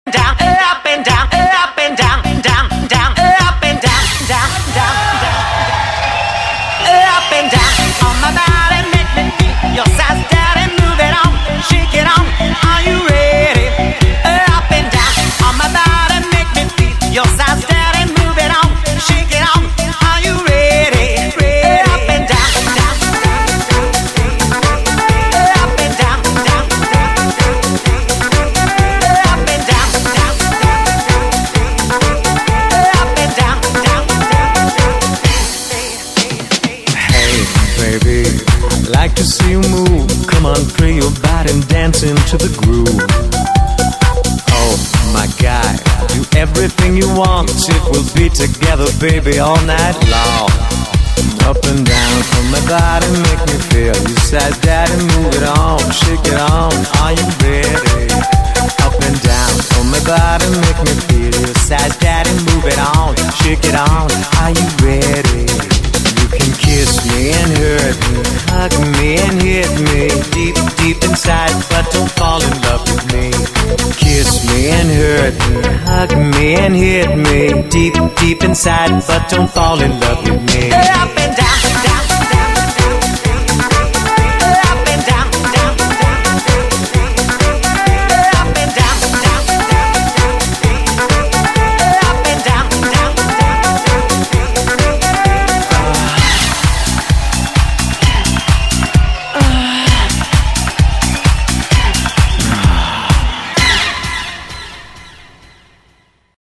BPM65-130
Audio QualityPerfect (High Quality)